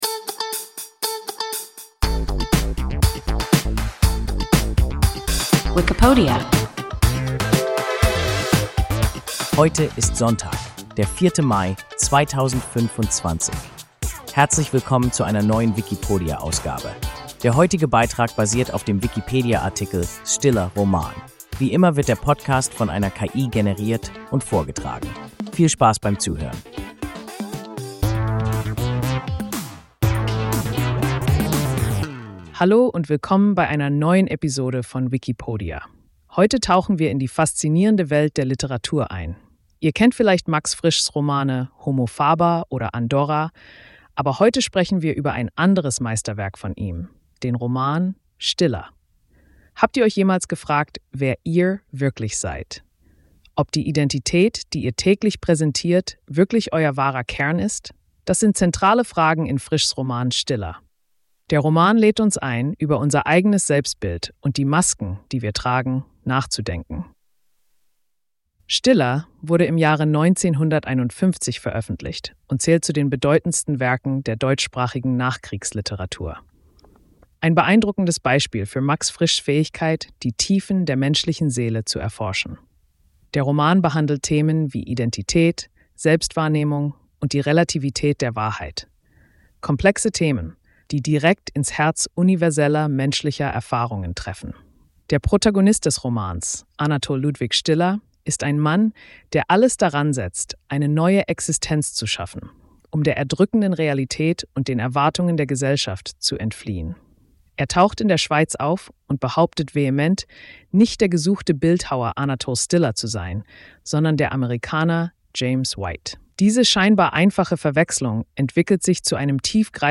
Stiller (Roman) – WIKIPODIA – ein KI Podcast